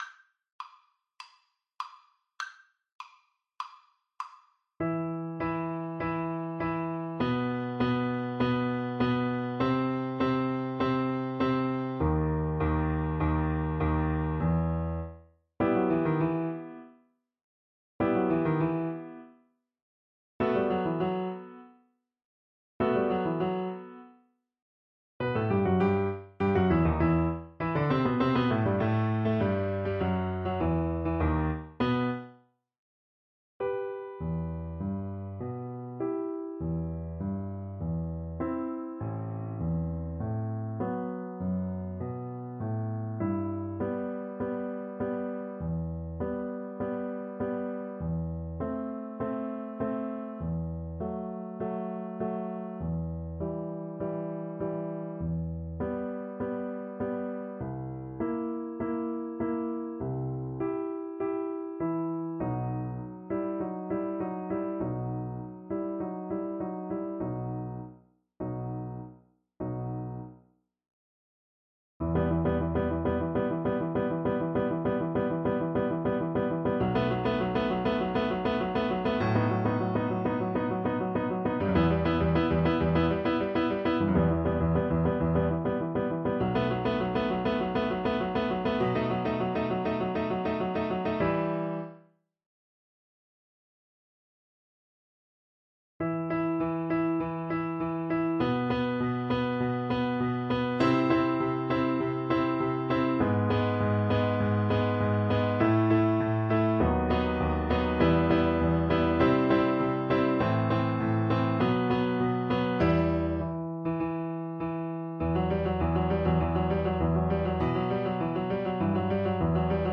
Play (or use space bar on your keyboard) Pause Music Playalong - Piano Accompaniment transpose reset tempo print settings full screen
Violin
4/4 (View more 4/4 Music)
Allegro con brio = c. 140 (View more music marked Allegro)
E minor (Sounding Pitch) (View more E minor Music for Violin )
Classical (View more Classical Violin Music)
Dramatic & Epic music for Violin